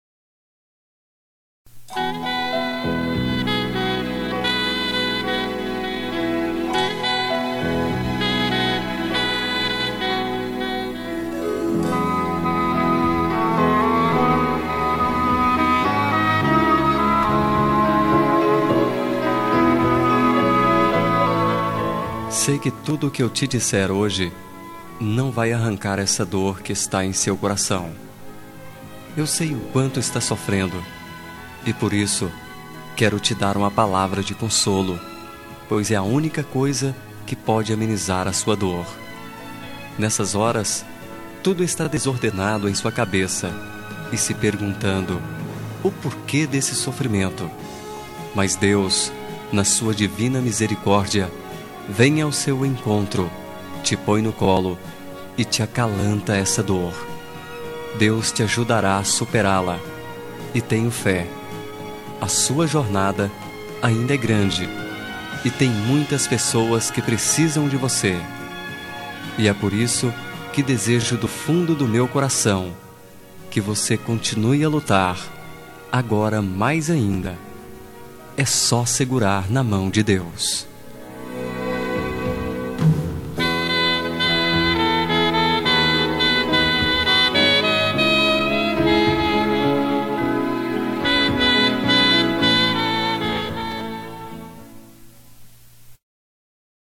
Telemensagem Pêsames – Voz Masculina – Cód: 5261